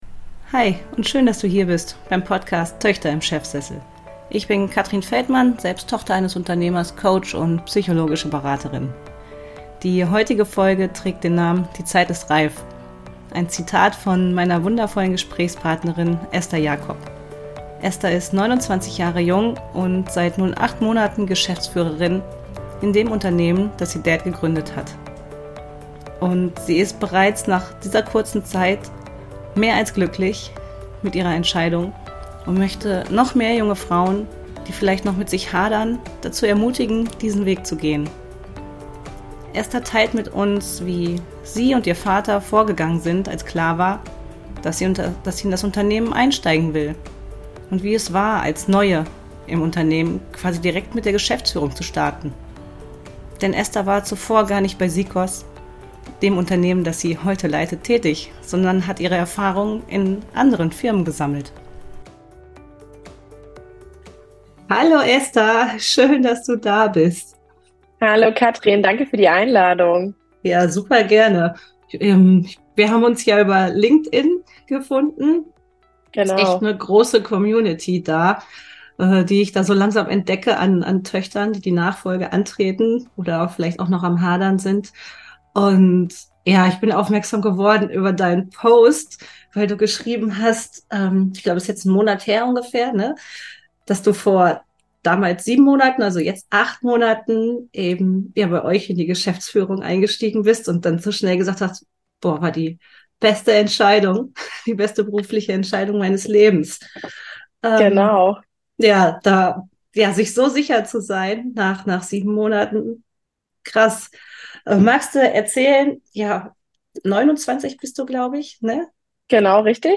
Die Zeit ist reif – Interview